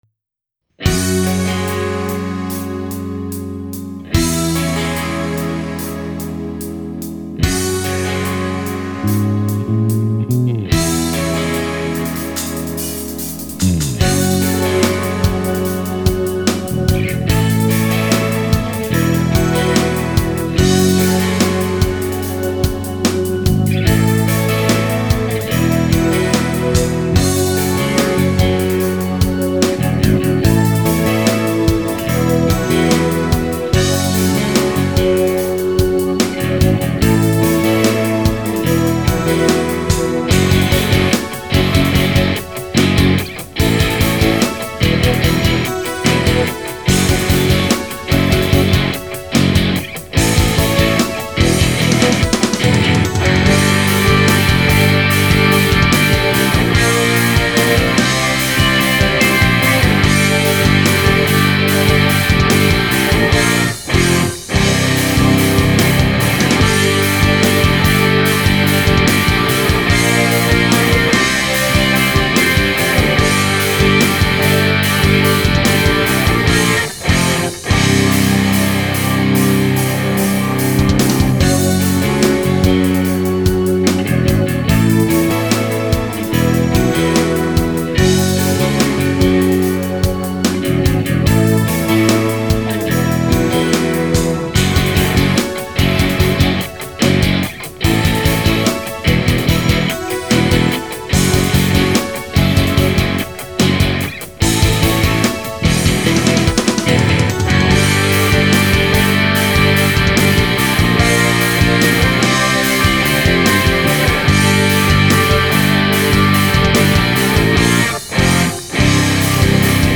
Instrumental, Rock